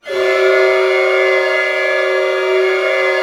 Index of /90_sSampleCDs/Roland LCDP08 Symphony Orchestra/ORC_ChordCluster/ORC_Clusters
ORC CLUST07R.wav